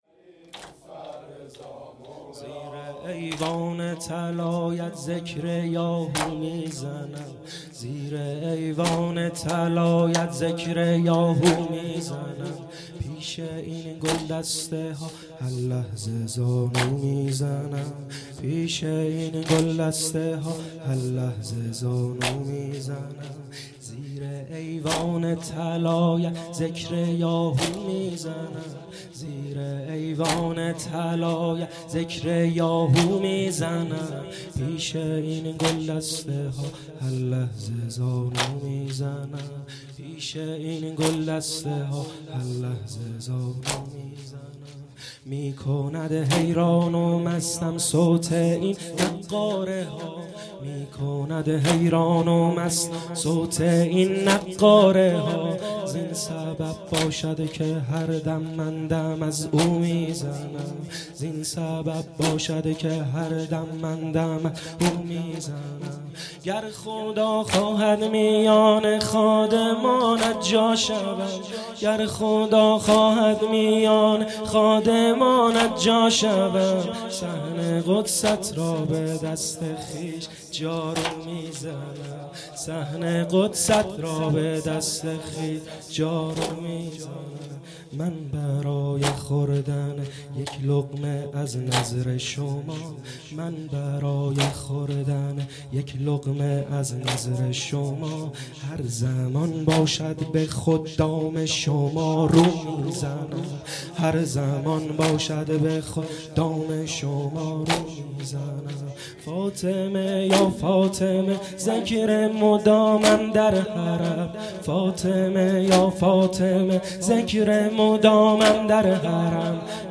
واحد- زیر ایوان طلایت ذکر یا هو میزند